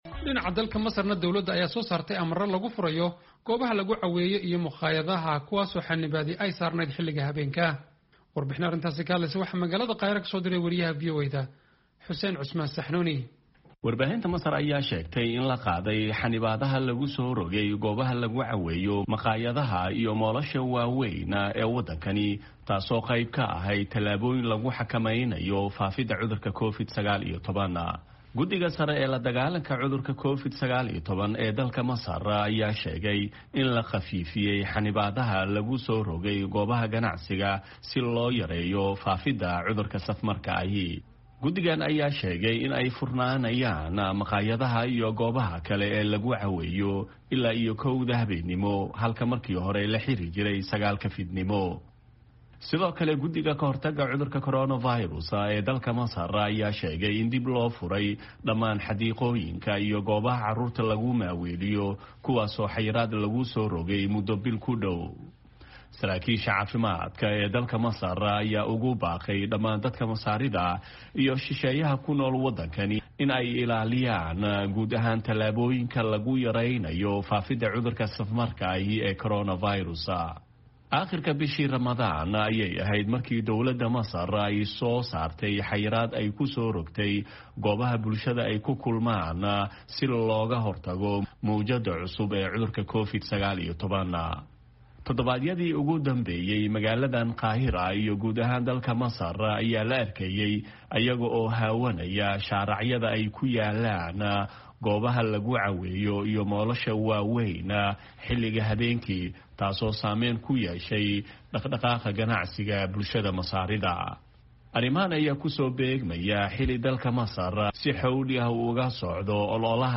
Warbixintan waxaa magaalada Qaahira ka soo diray